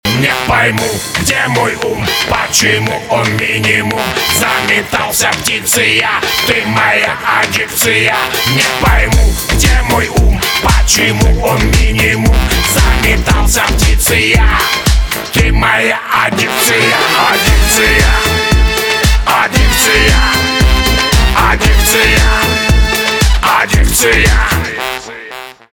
русский рок
труба
гитара , барабаны